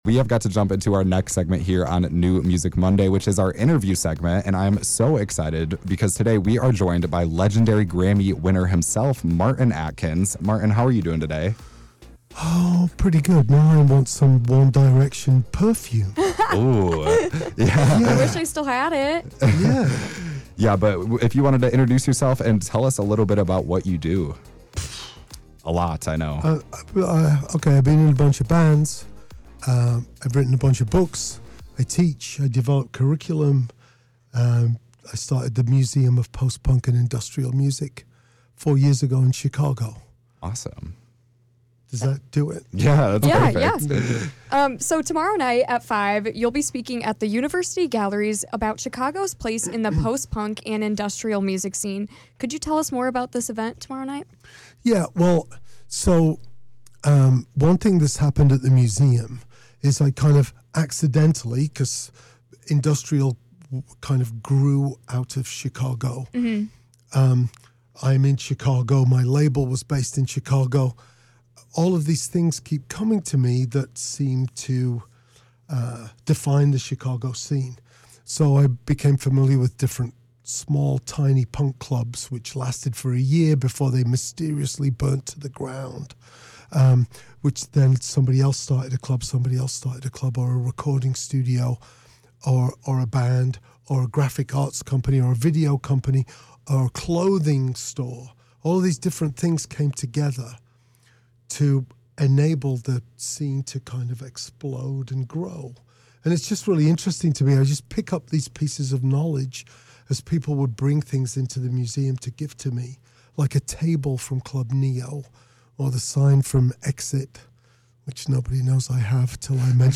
This week, Grammy award winner Martin Atkins was featured on New Music Monday.